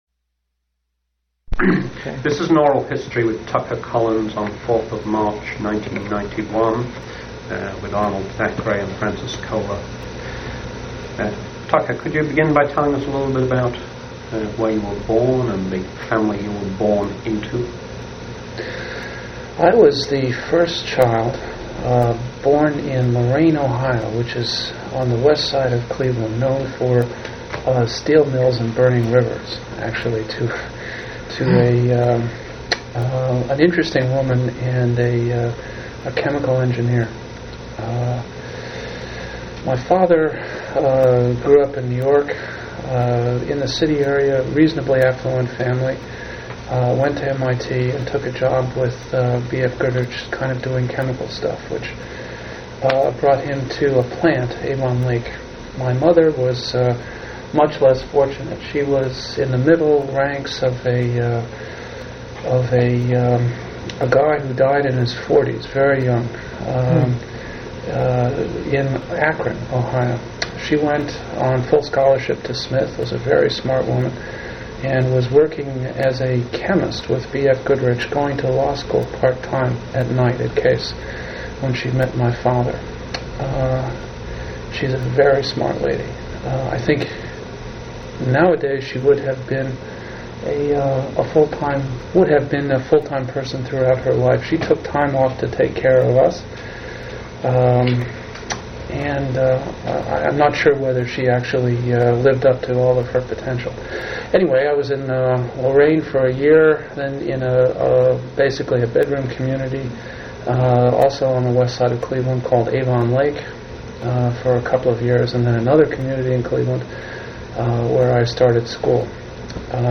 Oral histories
Place of interview California--Coronado